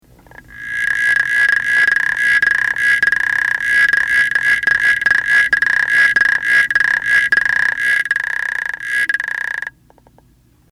Advertisement Calls
The call of Xenopus laevis is a 2-part trill, about 1/2 second, repeated up to 100 times per minute. Males have no vocal sacs and call from underwater during the day and at night.
advertisement call of a male African Clawed Frog made underwater